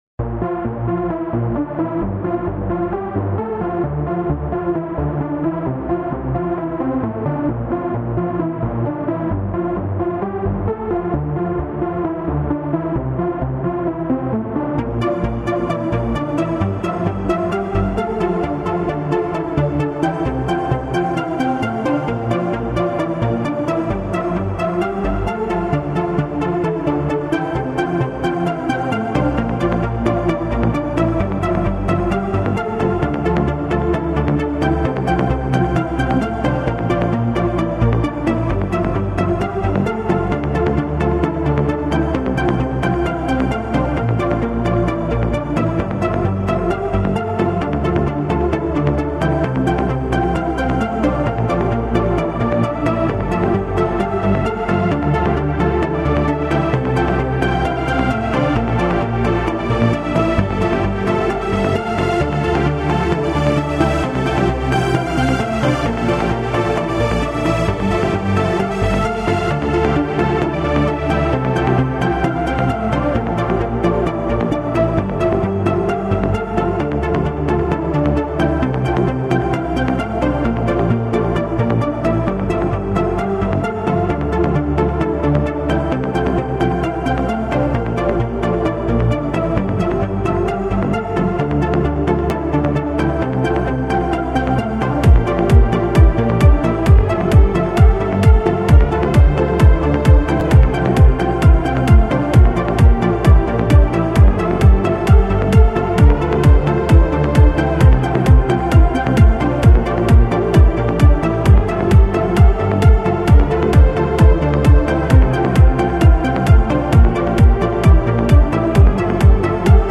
MIDI Tracker VST 008 (4.7M) - Trance track - .mp3 file (128kbps)
Real-time playing with channels and filter cut-offs.